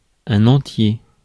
Ääntäminen
IPA: /ɑ̃.tje/